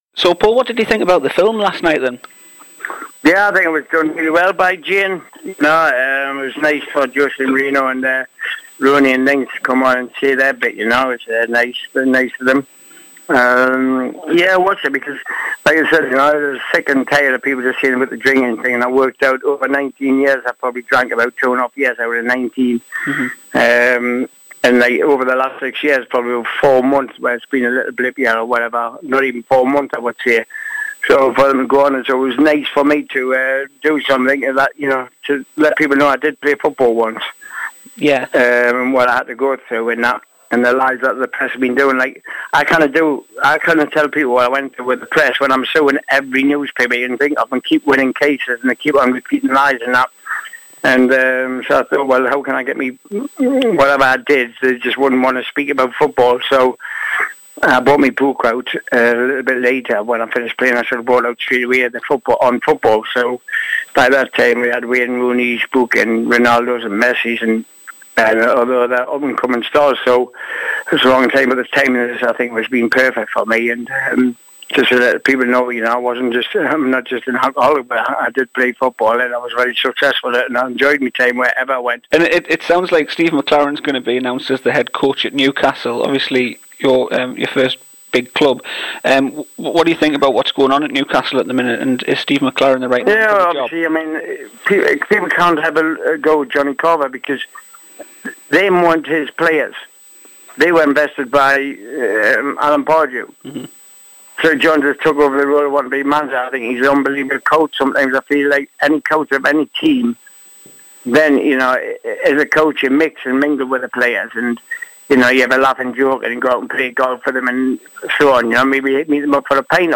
Interview: Paul Gascoigne